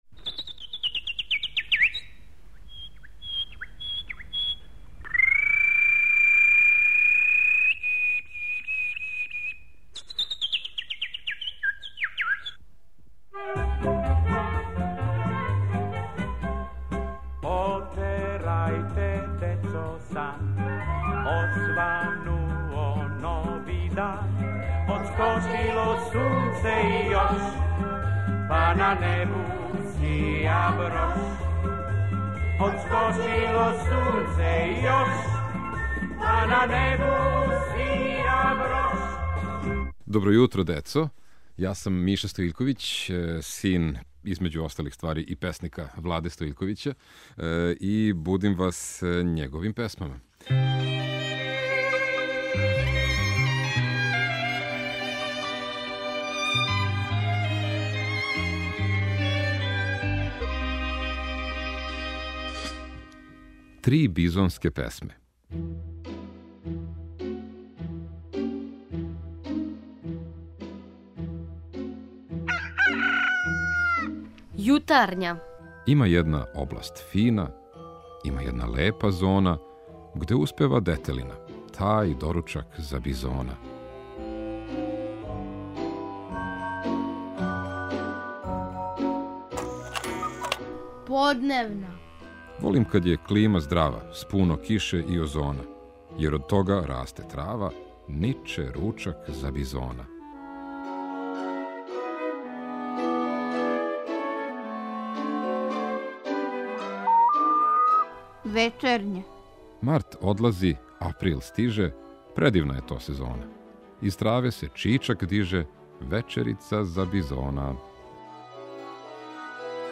Поезија, Влада Стоиљковић